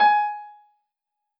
piano-ff-60.wav